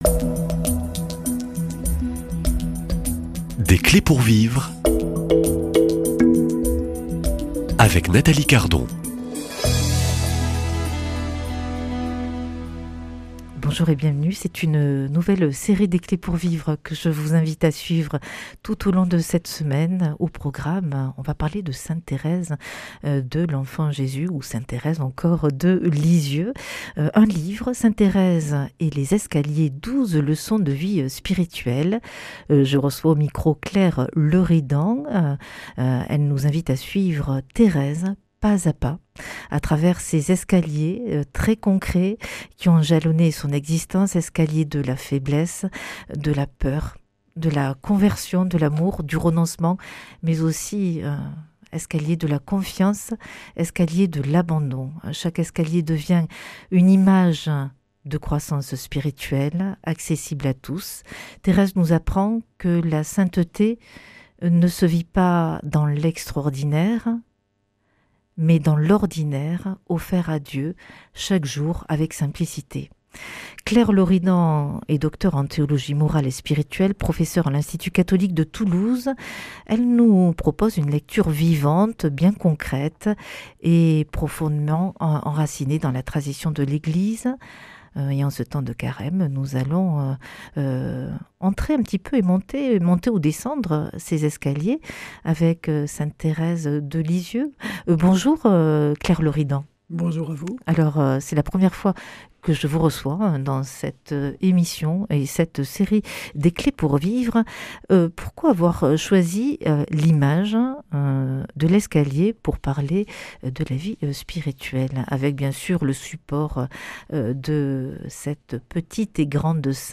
Je reçois au micro de la série Des clés pour vivre